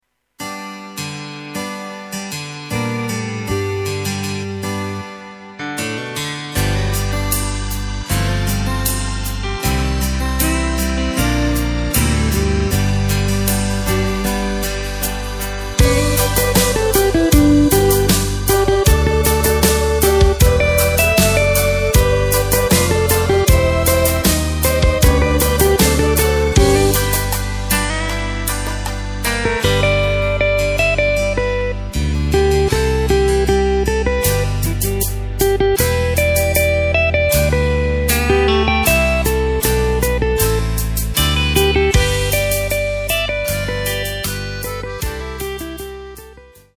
Takt:          4/4
Tempo:         156.00
Tonart:            G
Weihnachts-Song aus dem Jahr 2010!